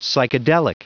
Prononciation du mot psychedelic en anglais (fichier audio)
Prononciation du mot : psychedelic